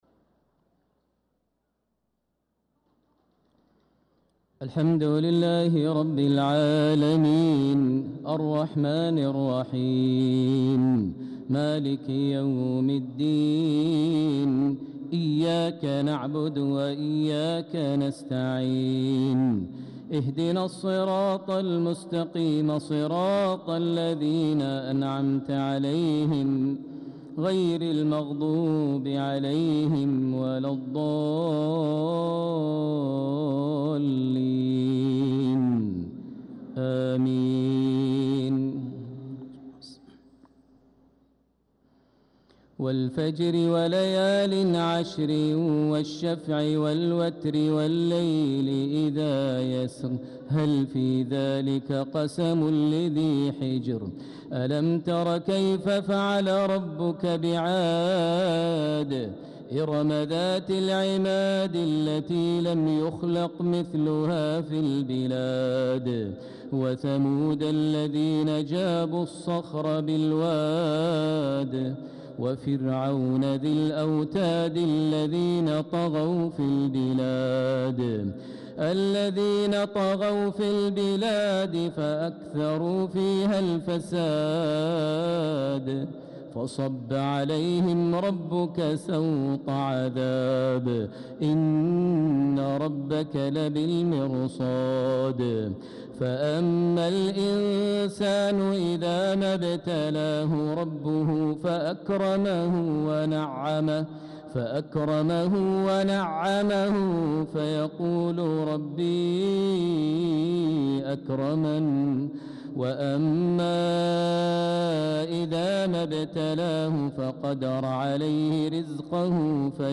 صلاة العشاء للقارئ ماهر المعيقلي 2 ذو الحجة 1445 هـ
تِلَاوَات الْحَرَمَيْن .